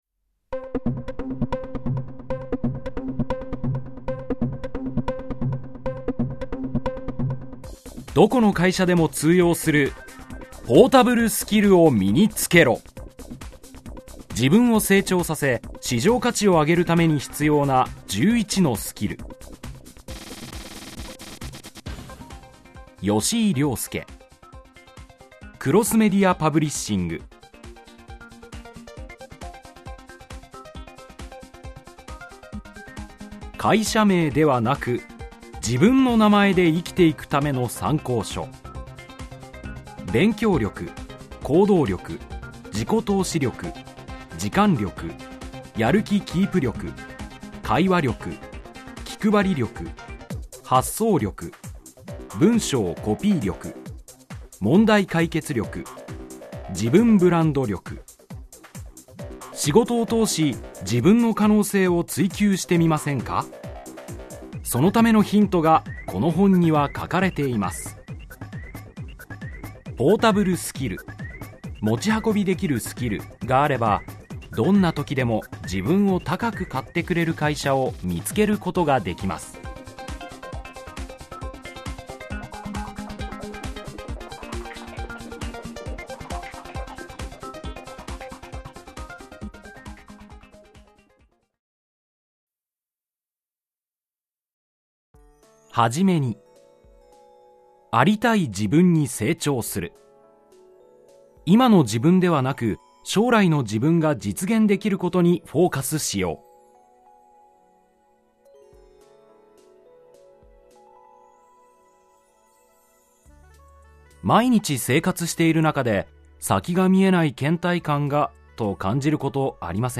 [オーディオブックCD] どこの会社でも通用する、ポータブル・スキルを身につけろ!